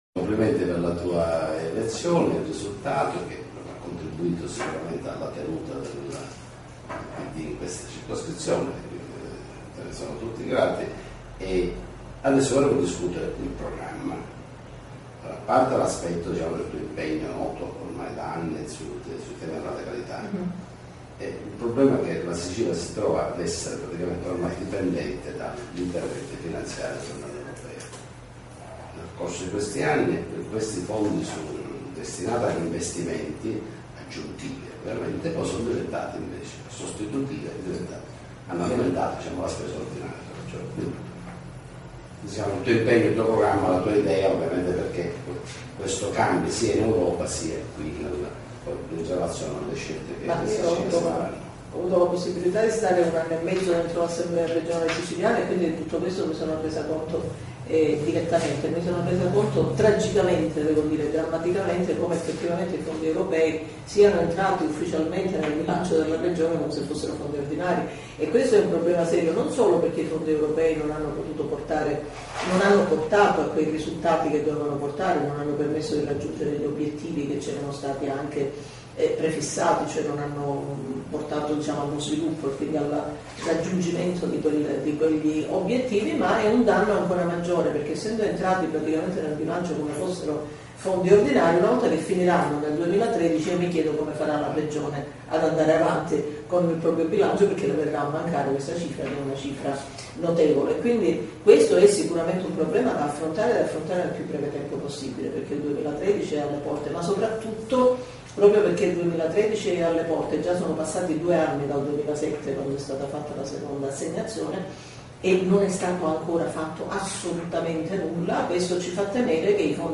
Parte video Durata Visite MP3 Ascolta Mp3 Intervista a Rita Borsellino 30' 34'' 1120